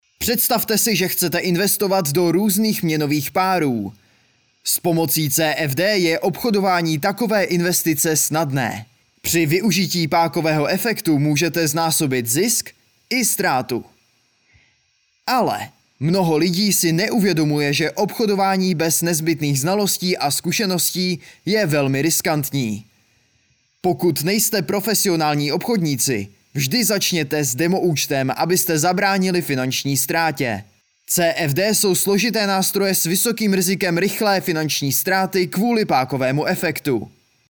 Hlas pro Vaše video! (voiceover)
K dispozici mám profesionální mikrofon a zvuk jsem schopen sám upravit a poté odeslat v nejvyšší možné kvalitě.